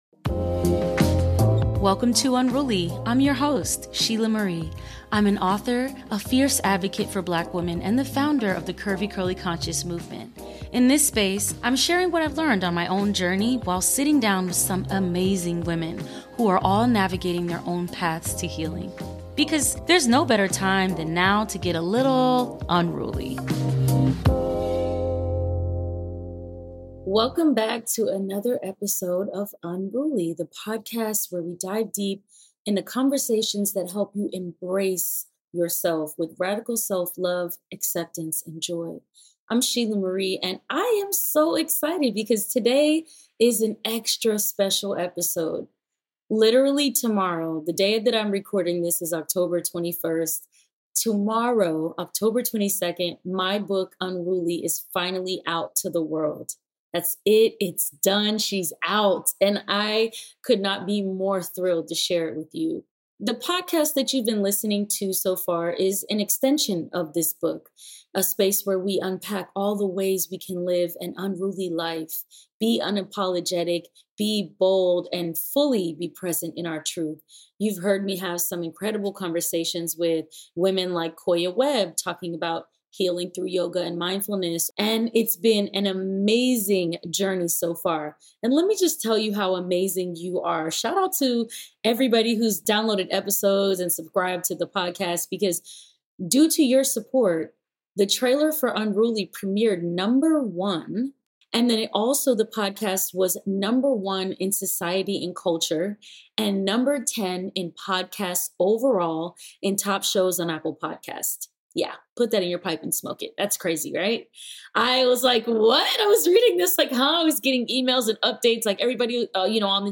Solo Episode: My Journey As a First Time Author